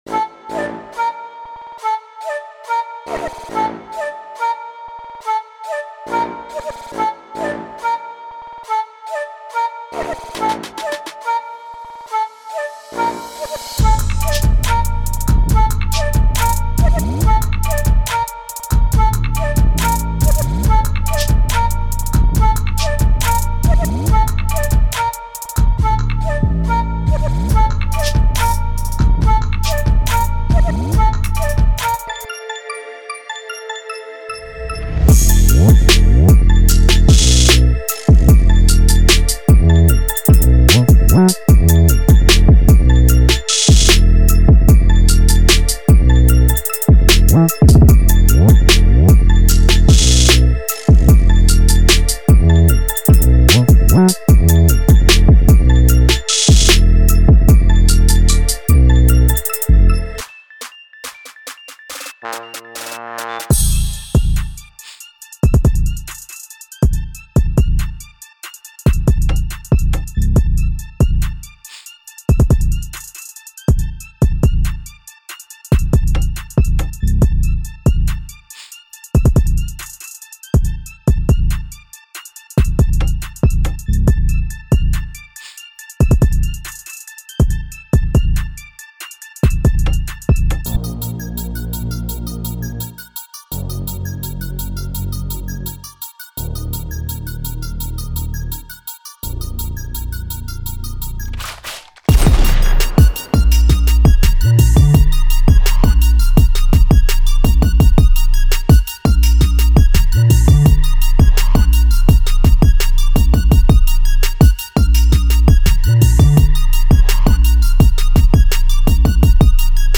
1 – Flute
1 – Piano
• 75-145 BPM